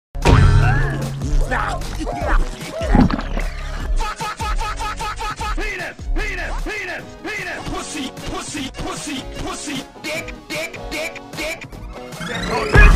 Memes
Goofy Ahh Slime And Swearing